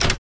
button_simple.wav